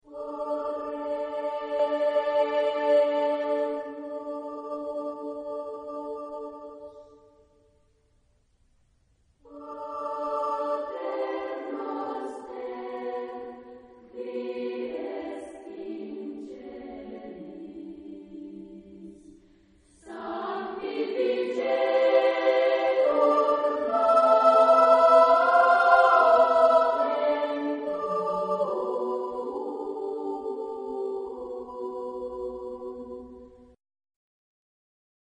SSAA (4 voix égales de femmes).